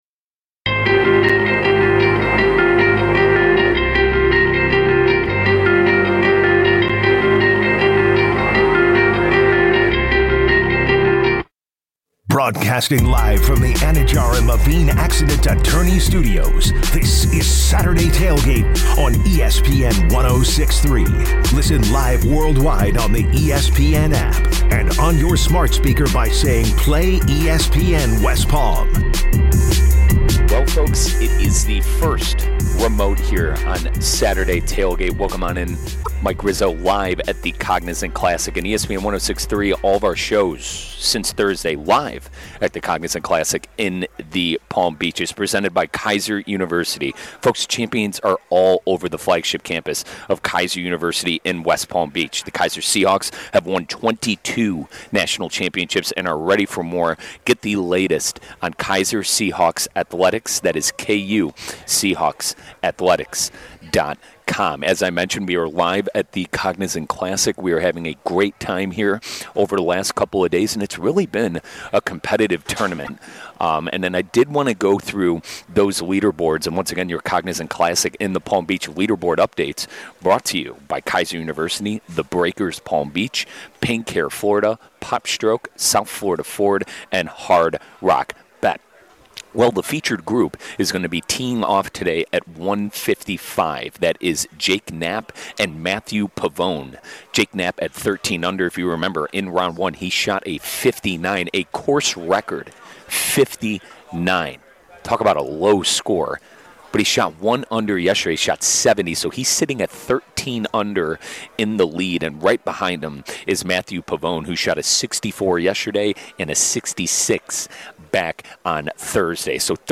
Saturday TailGate - Live From The Cognizant Classic! | SATU